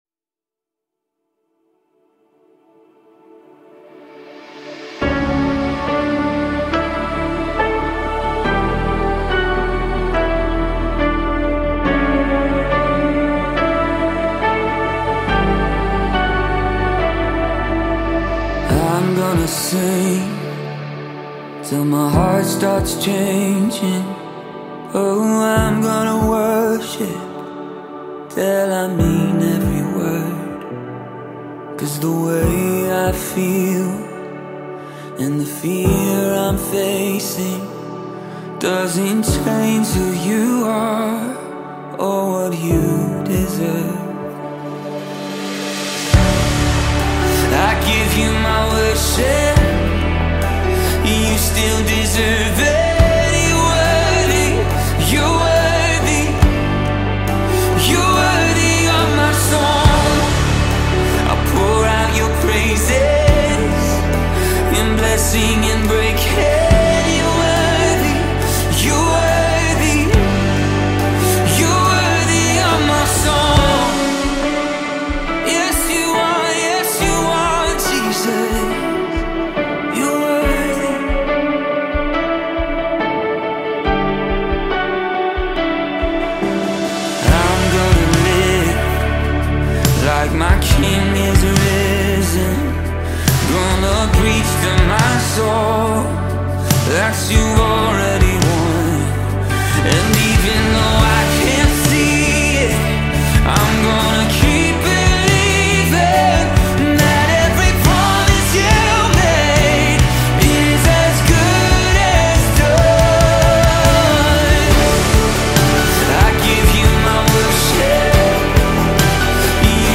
worship